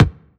TI100BD1  -L.wav